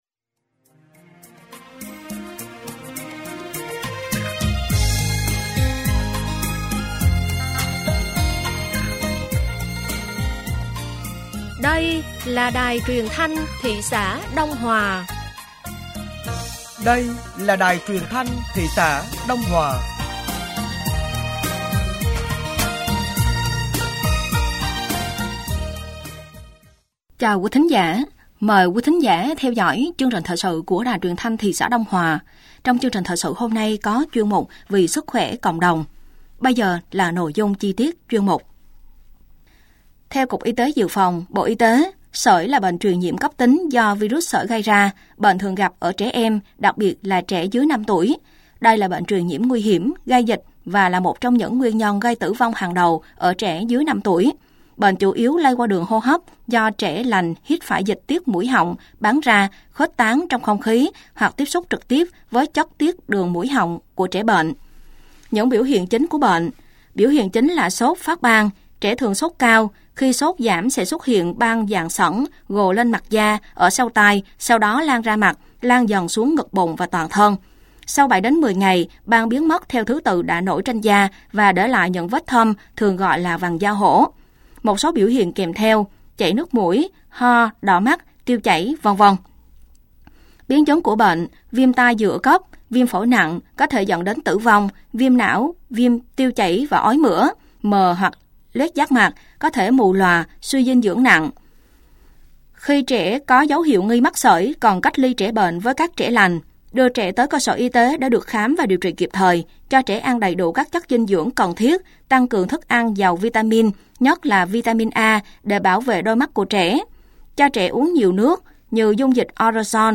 Thời sự tối ngày 15 và sáng ngày 16 tháng 9 năm 2024